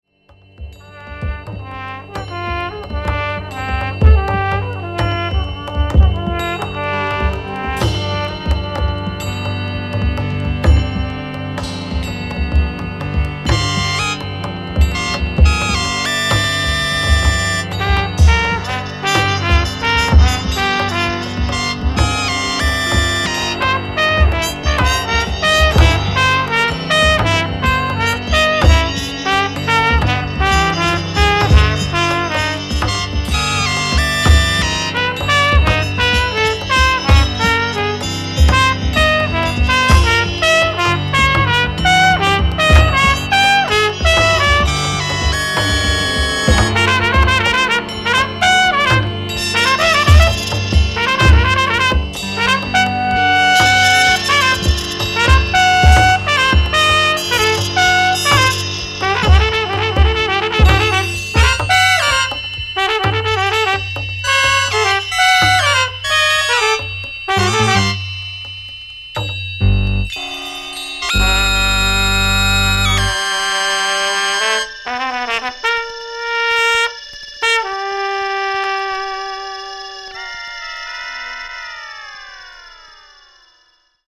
キーワード：即興　ミニマル　エレクロアコースティック